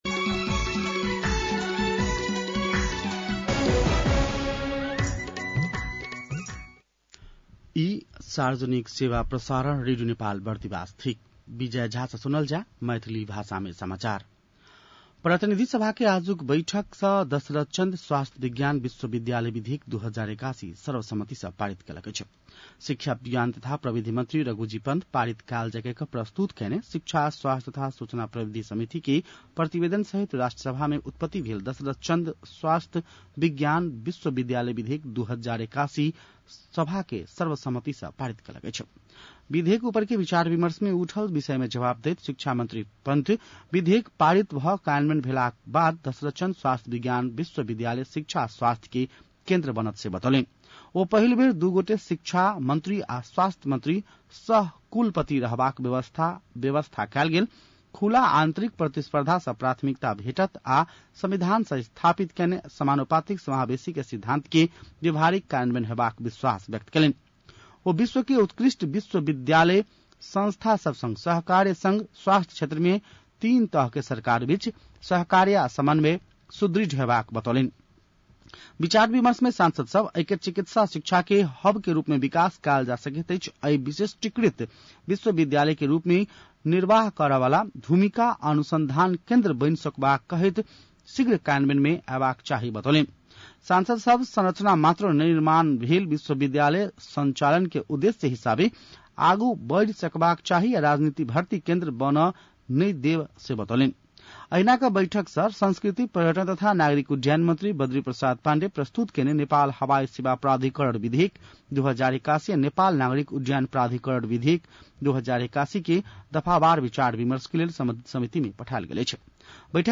मैथिली भाषामा समाचार : ४ जेठ , २०८२